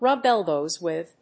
rúb [tóuch] élbows with…